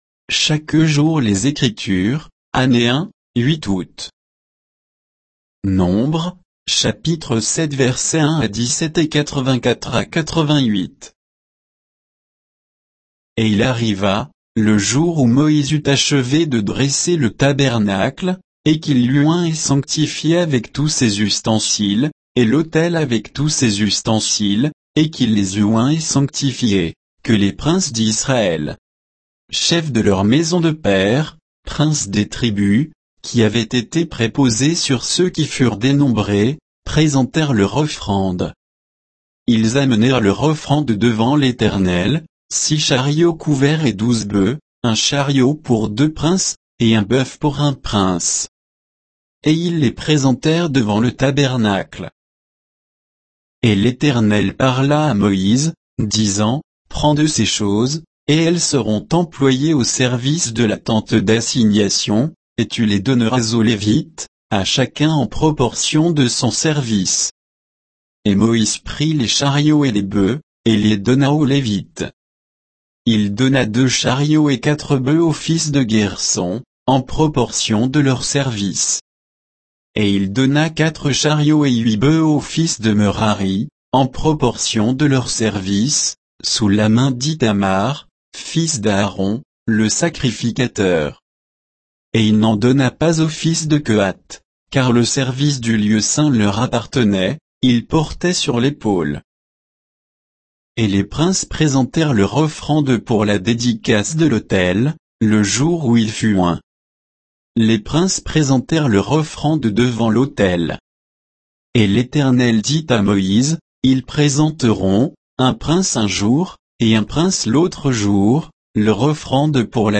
Méditation quoditienne de Chaque jour les Écritures sur Nombres 7